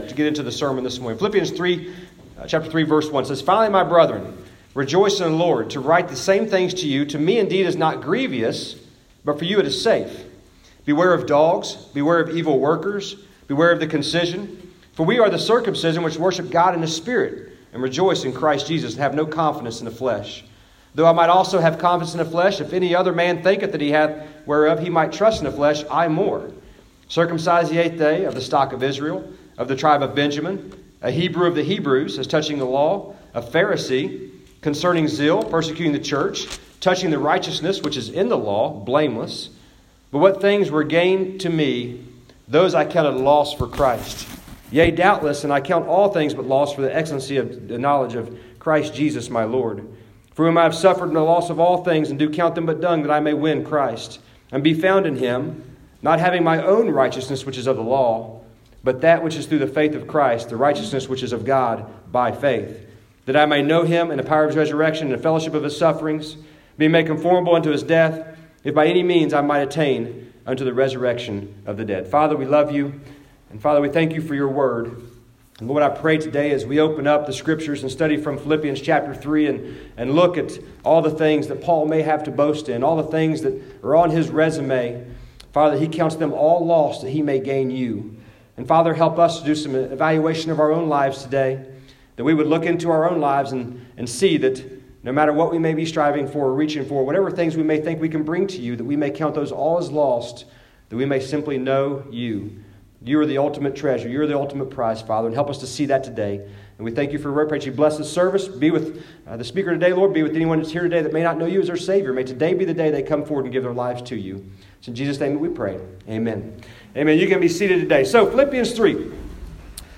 Guest speaker
on Sunday morning